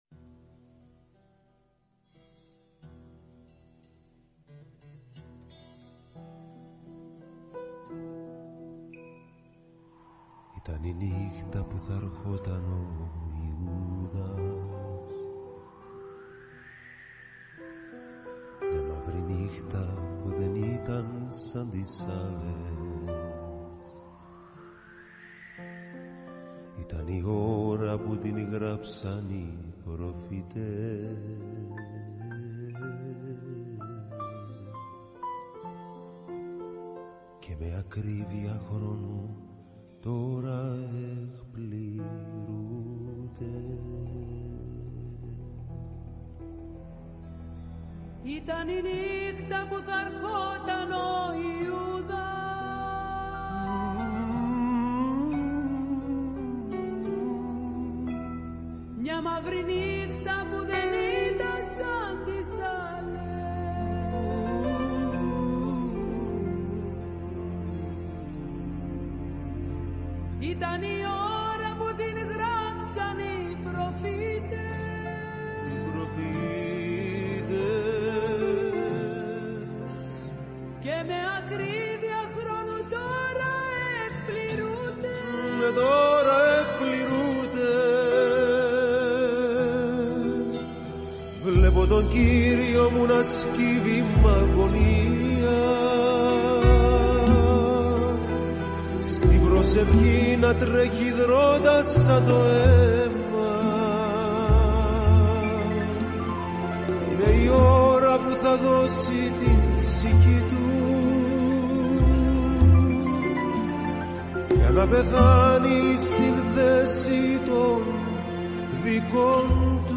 ΤΟ ΤΡΑΓΟΥΔΙ ΣΤΗΝ ΑΡΧΗ ΚΑΙ ΣΤΟ ΤΕΛΟΥΣ ΤΟΥ ΜΗΝΥΜΑΤΟΣ